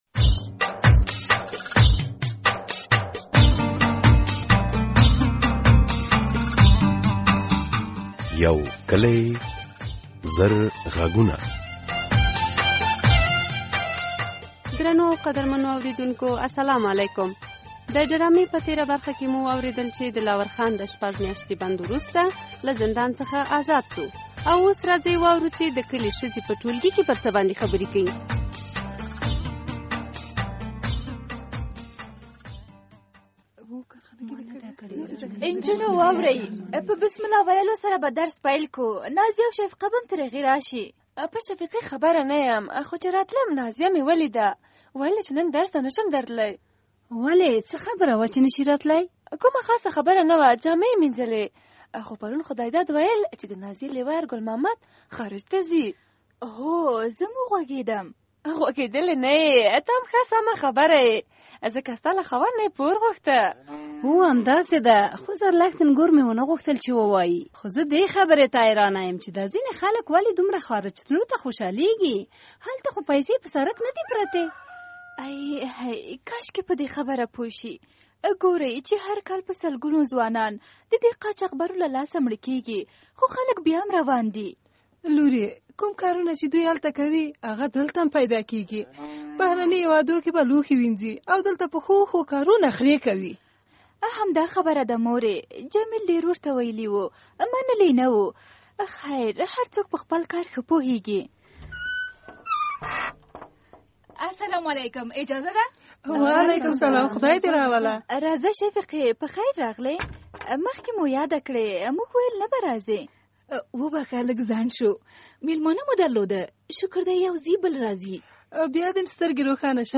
که ځوانان خارج ته زړه ښه کوي کورنی او مشران په کوم حالت پریږدي؟ د یوکلي زرغږونو د دې اونی په ډرامه کې د ګل محمد له تګه وروسته حالت بیان شوی او په کې ګڼ نور ټولنیز مسایل اوری.